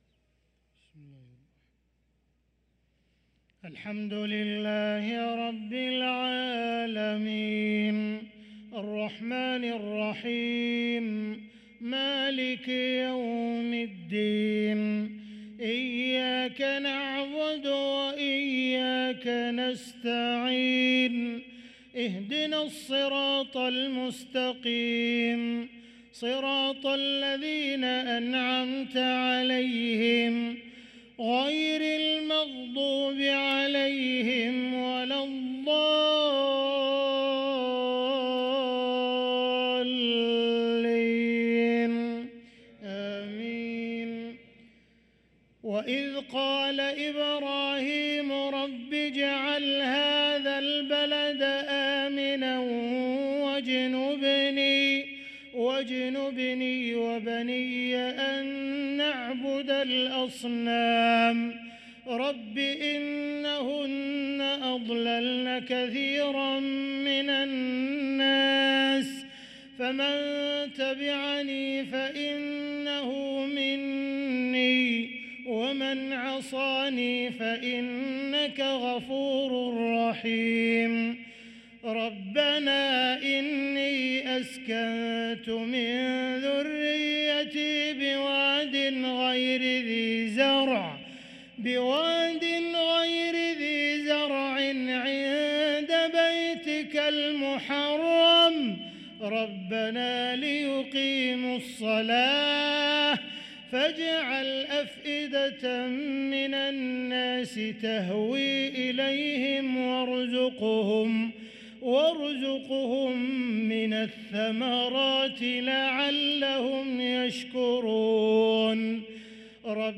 صلاة العشاء للقارئ عبدالرحمن السديس 10 ربيع الآخر 1445 هـ
تِلَاوَات الْحَرَمَيْن .